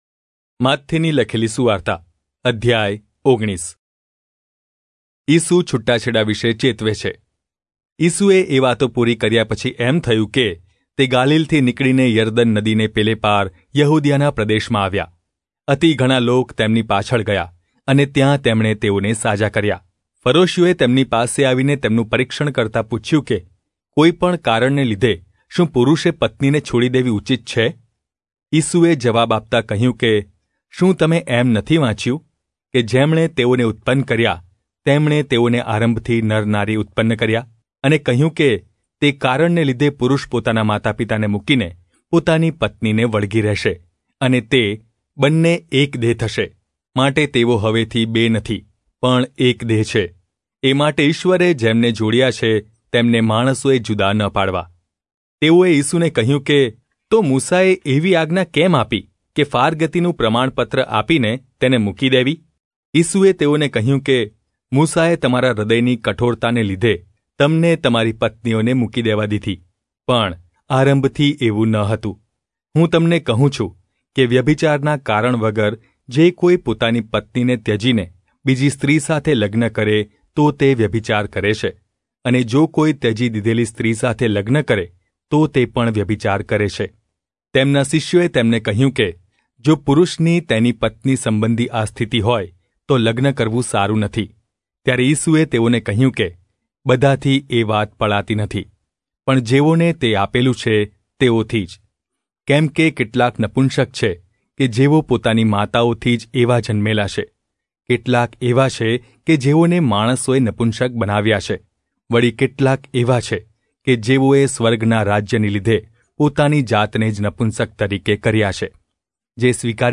Gujarati Audio Bible - Matthew 15 in Irvgu bible version